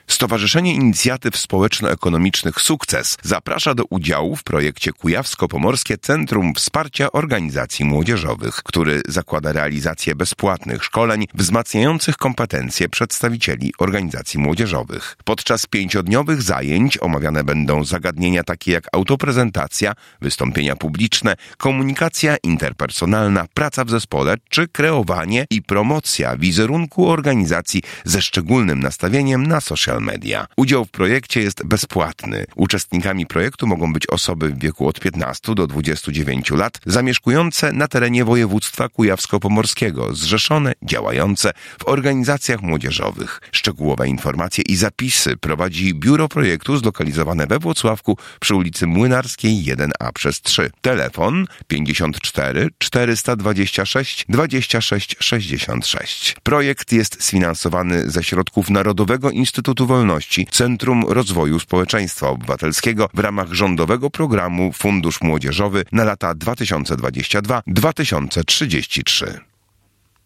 Spot reklamowy projektu: